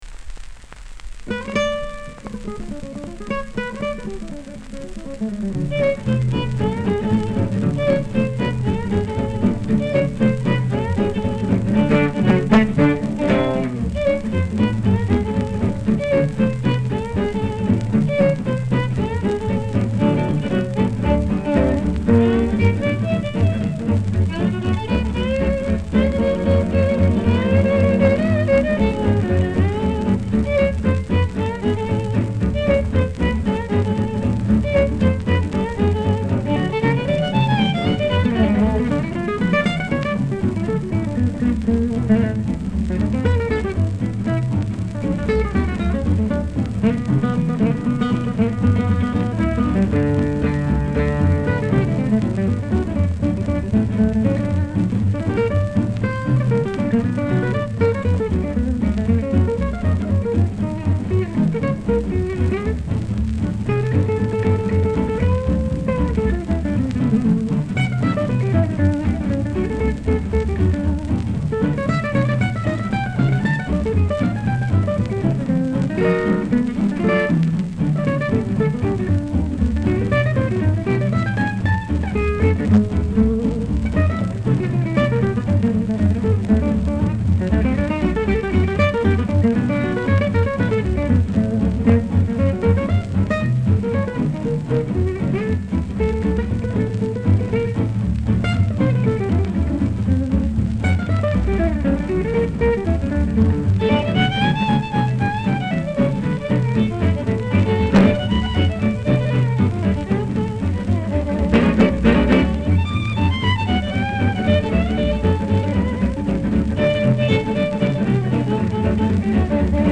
vocal
clarinet, alto sax
tenor sax
piano
guitar
drums, shellac 10"
USA (rec. New York) 1947, 78.00 об/мин.